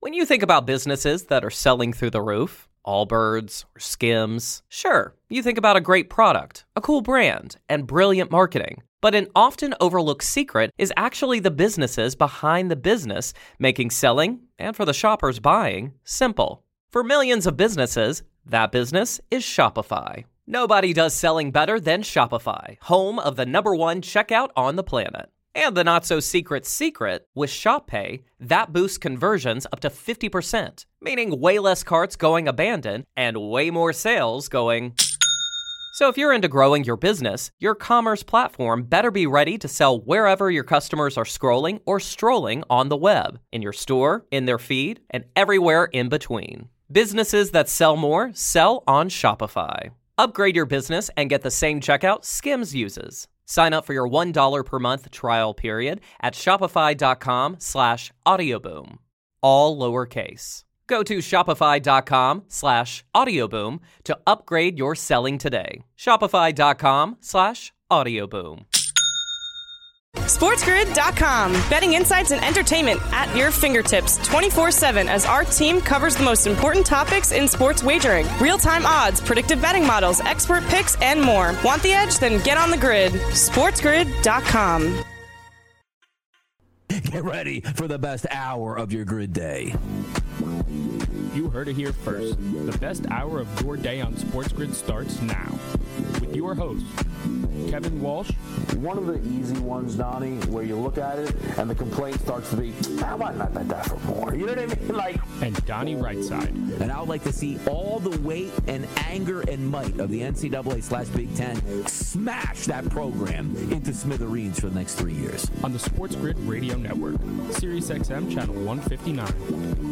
All this, your calls, his best futures in the NFL, and more!